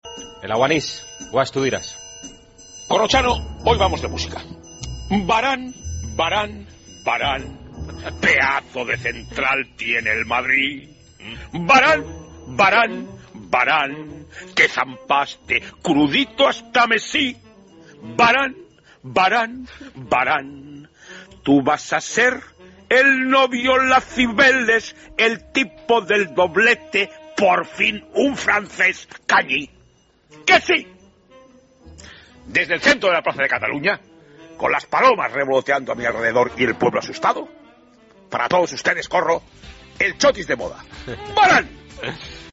AUDIO: En el Aguanís, Tomás Guash le dedica una canción muy especial a Varane.